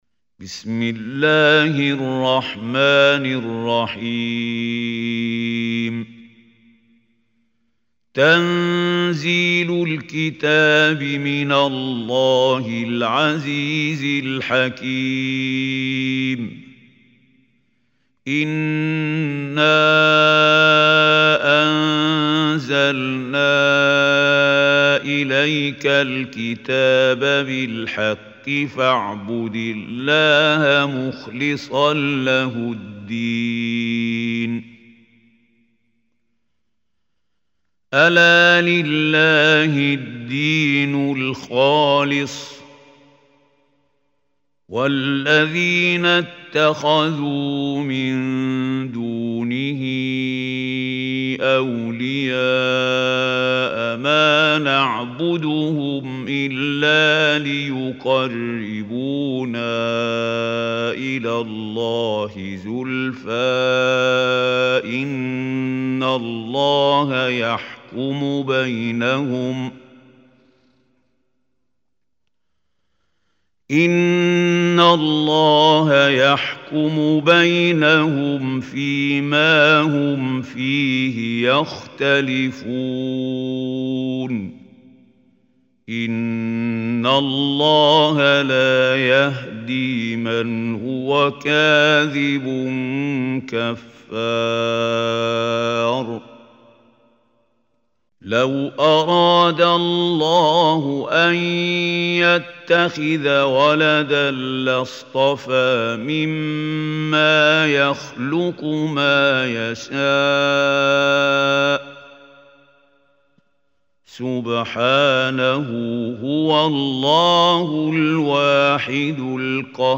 Surah Az Zumar MP3 Recitation Mahmoud Hussary
Surah Az Zumar, listen or play online mp3 tilawat / recitation in Arabic in the beautiful voice of Sheikh Mahmoud Khalil Al Hussary.